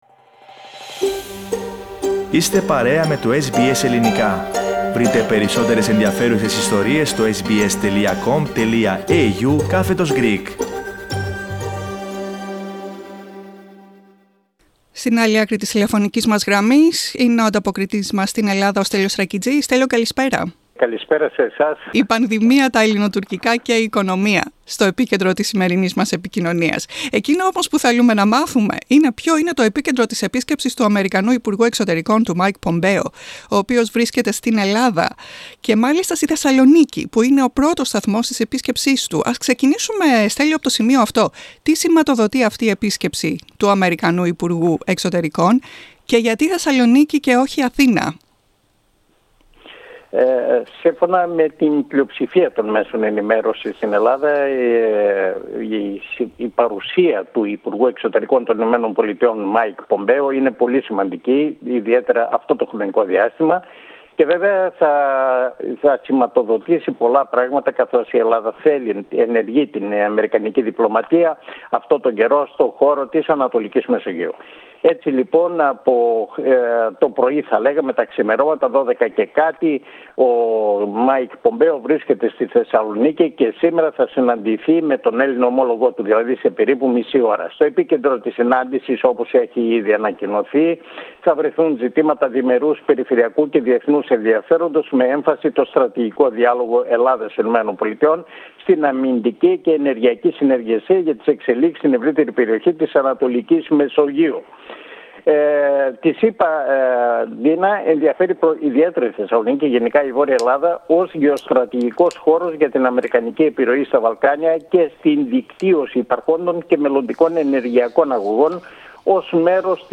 Η πανδημία, τα Ελληνοτουρκικά και η οικονομία, είναι τα βασικά θέματα της εβδομαδιαίας ανταπόκρισης από την Αθήνα.